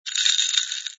sfx_ice_moving05.wav